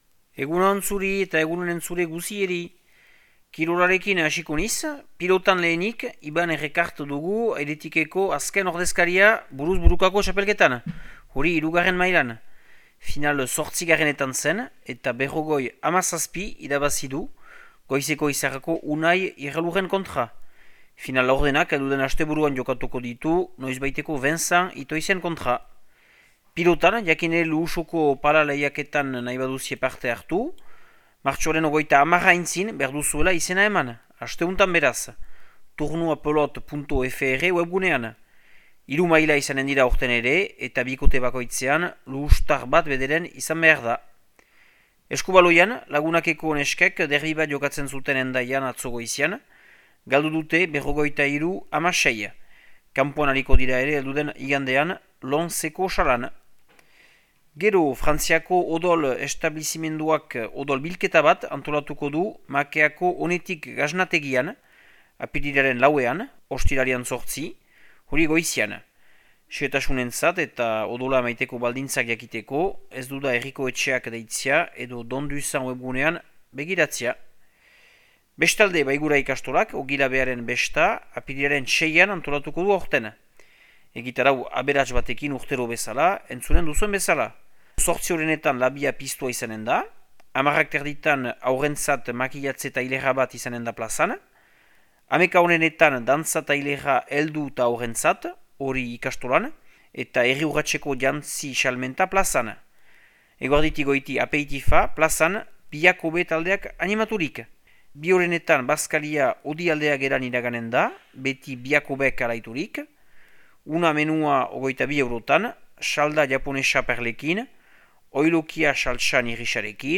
Martxoaren 24ko Makea eta Lekorneko berriak